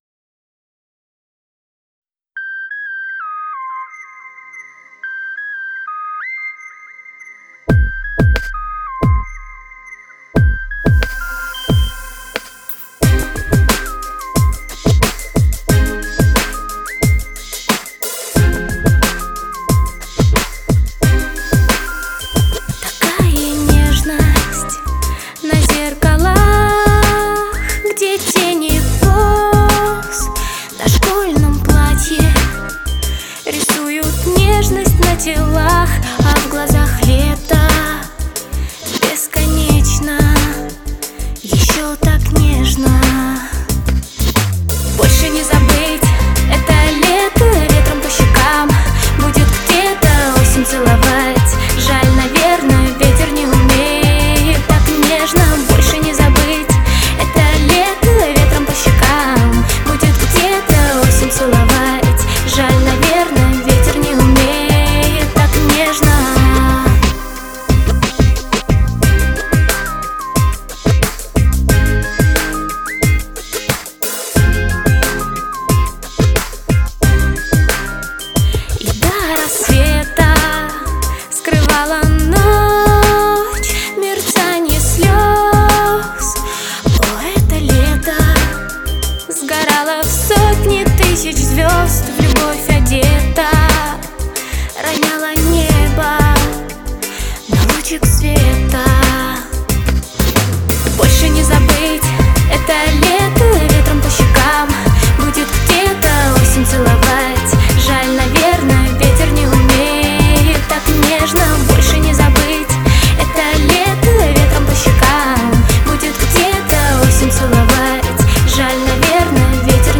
r’n’b remix